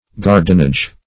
Search Result for " guardenage" : The Collaborative International Dictionary of English v.0.48: Guardenage \Guard"en*age\, n. Guardianship.